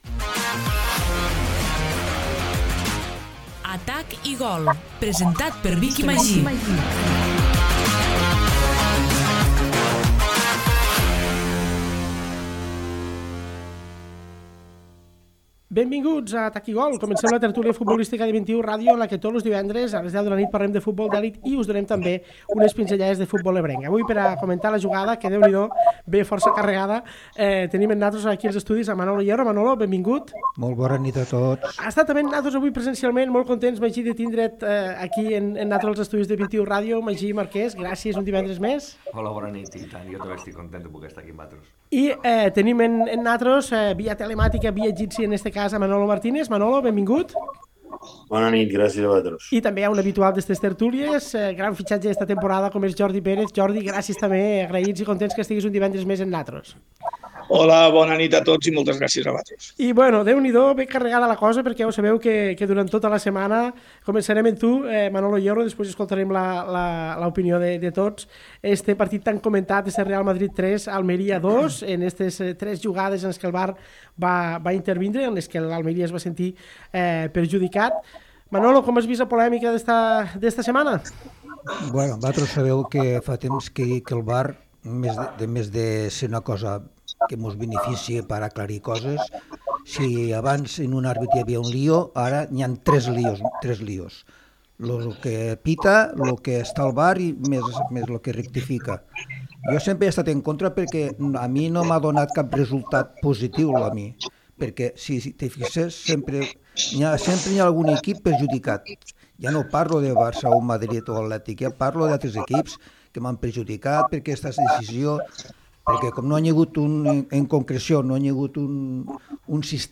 Avui farem un nou programa d'Atac i gol, la tertúlia futbolística de 21 Ràdio en la que tots els divendres, a les 22.00, parlem del futbol d'elit i us donem